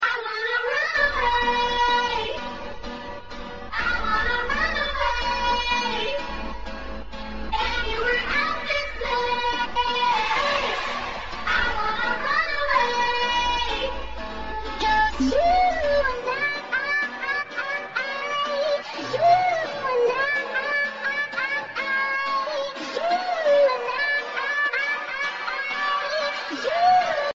Category: Games Soundboard